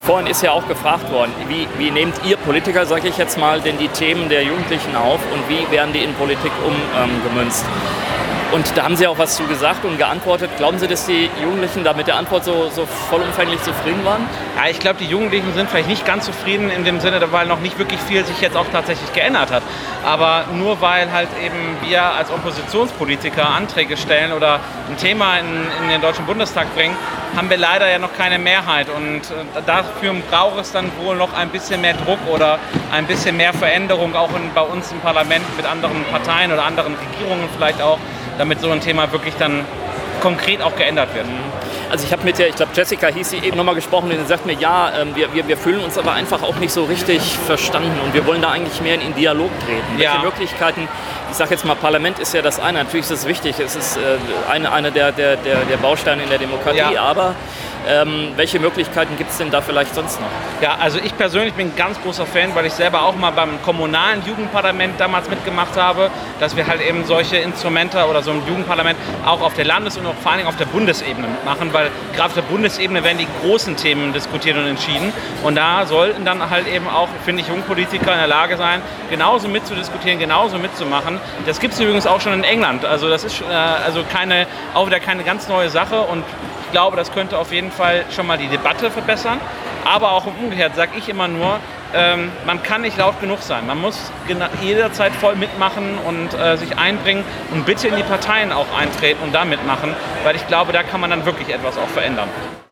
Interview mit Roman Müller-Böhm (MdB):